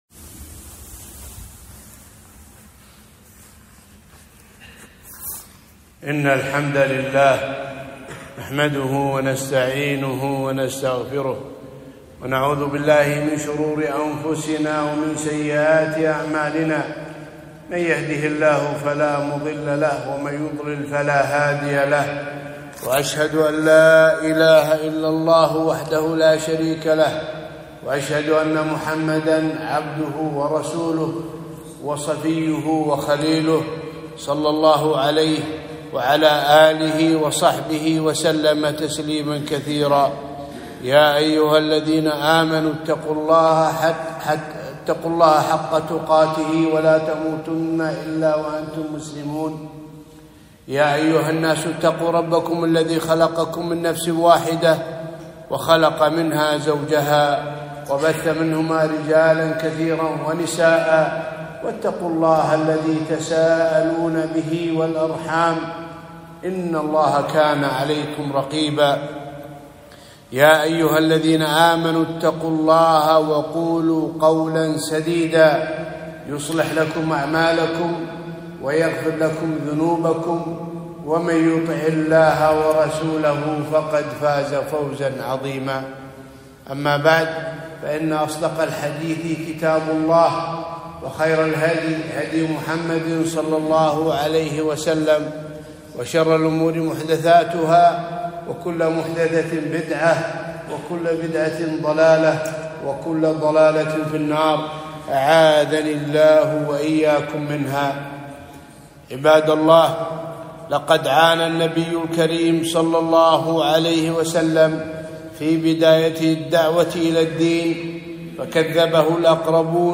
خطبة - عبر ودروس من حديث معراج النبي ﷺ